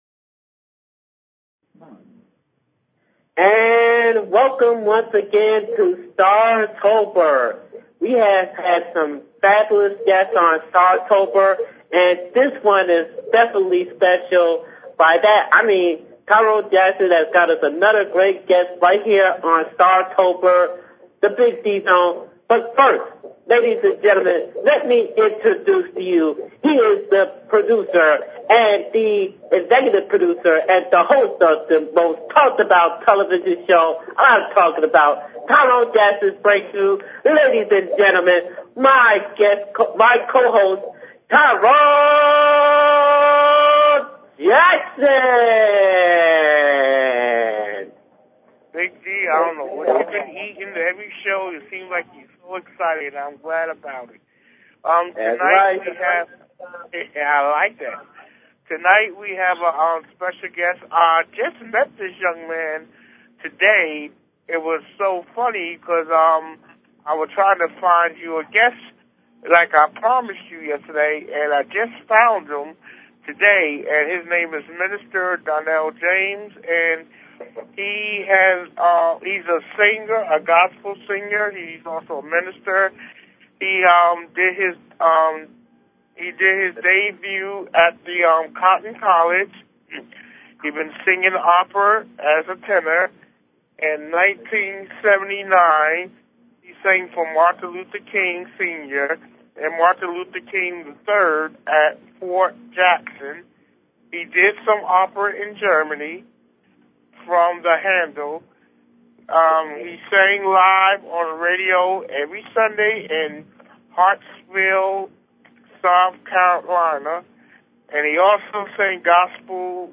Talk Show Episode
The show will feature artists from R&B, RA, HIP HOPGOSPEL, POETRY, ROCK, AND MAY BE MORE!